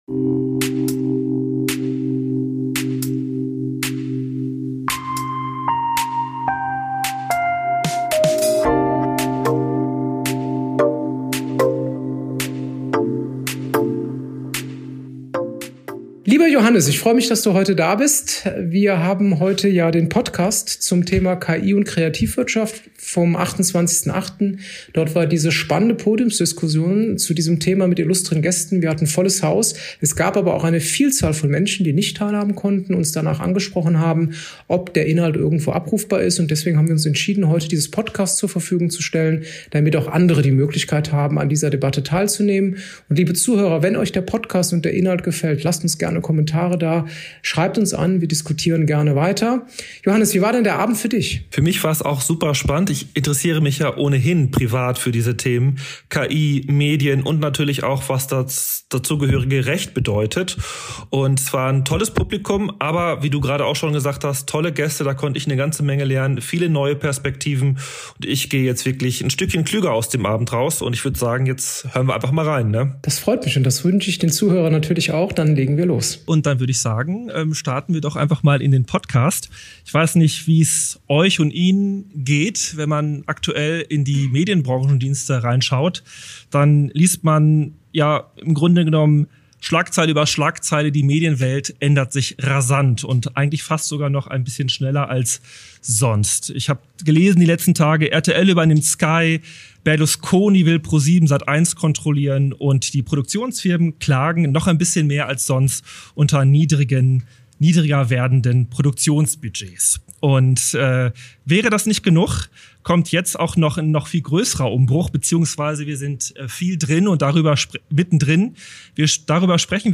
Sommerfest-Podiumsdiskussion ~ Offshore Podcast
Beschreibung vor 6 Monaten Gemeinsam mit dem Legal Tech Lab Cologne haben wir in diesem Sommer ein besonderes Highlight gestaltet: Unser Sommerfest mit einer lebendigen Podiumsdiskussion zum Thema „KI & Kreativwirtschaft“. Es wurde spannend und kontrovers diskutiert: Über die Chancen und Herausforderungen von KI in der Rechtsbranche und für Kreative, über die Frage, wie KI bestehende Geschäftsmodelle verändern oder sogar ersetzen wird, und darüber, welche neuen Möglichkeiten sich eröffnen können.